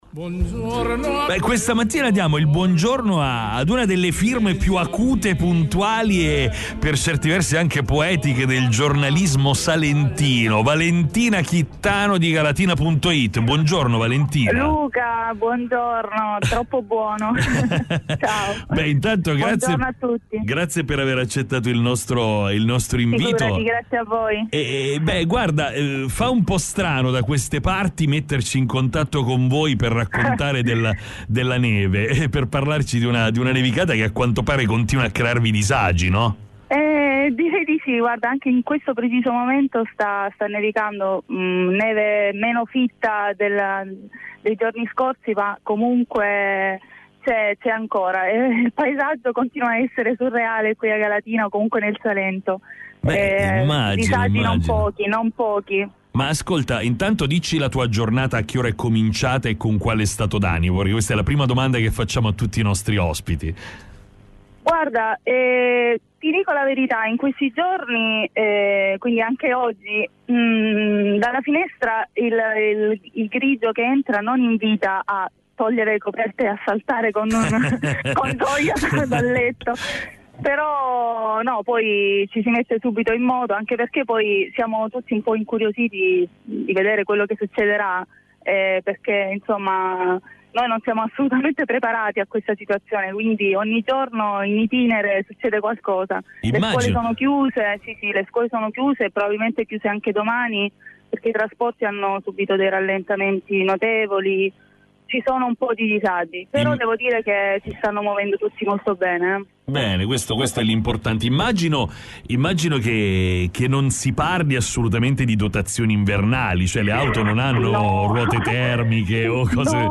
L'eccezionalità delle nevicate di questi giorni in Salento ha incuriosito anche il resto dell'Italia.